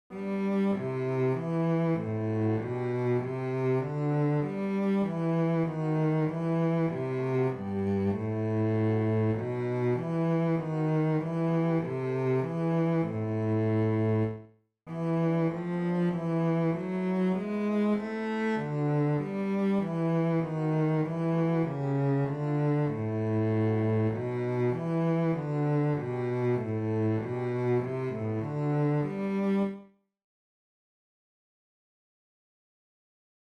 Chorproben MIDI-Files 510 midi files